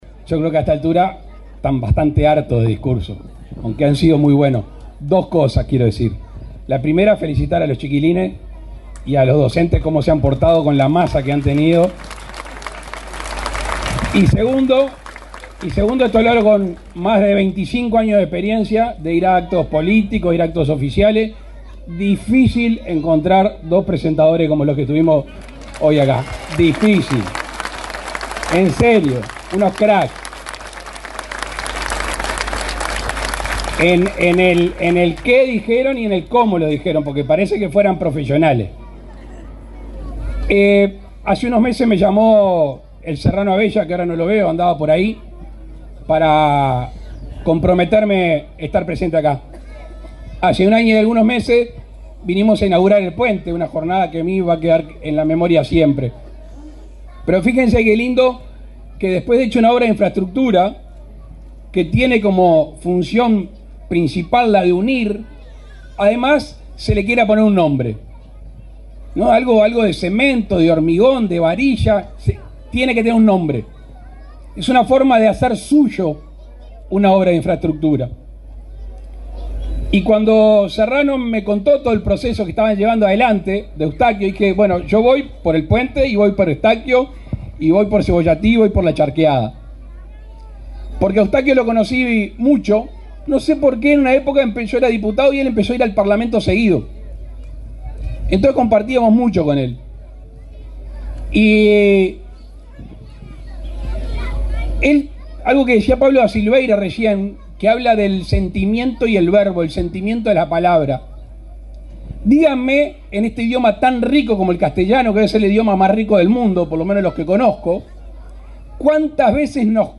Palabras del presidente Luis Lacalle Pou
El presidente Luis Lacalle Pou participó, este viernes 1.°, en la presentación de una publicación sobre el cantautor olimareño Eustaquio Sosa, en la